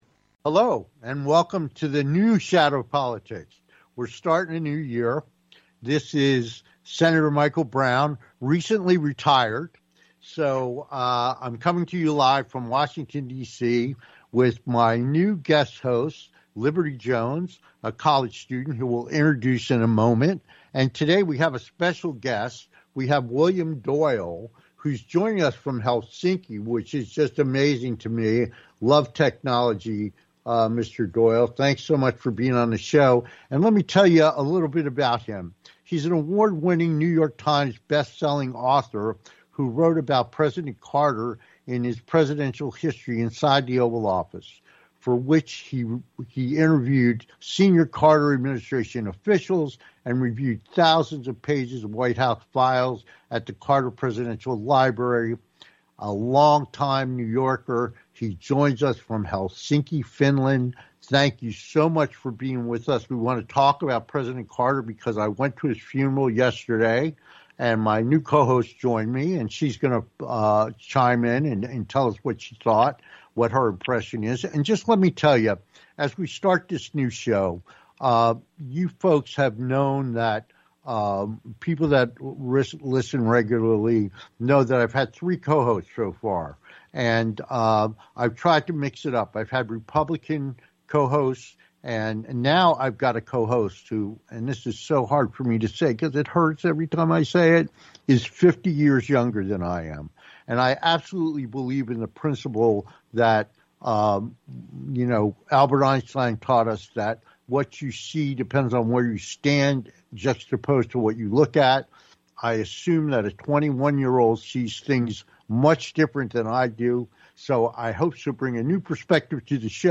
Shadow Politics Talk Show